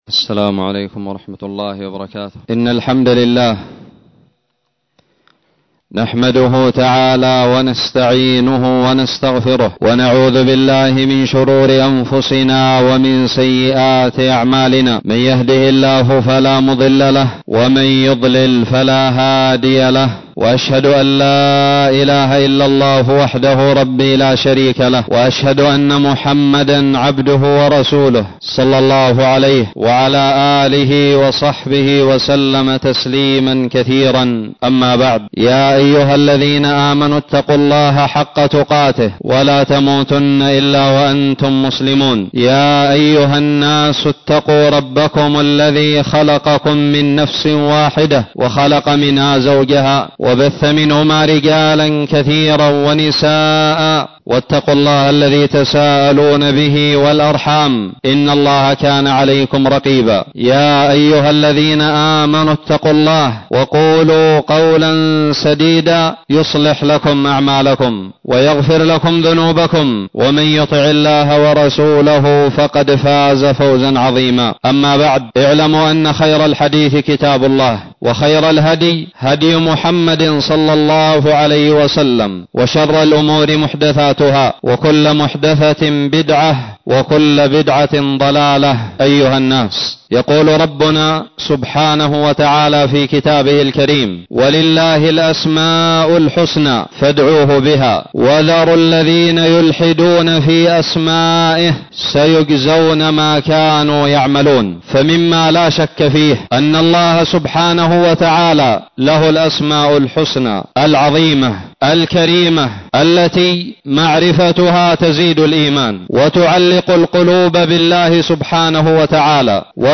خطب الجمعة
ألقيت بدار الحديث السلفية للعلوم الشرعية بالضالع في 17 جمادى الآخرة 1440هــ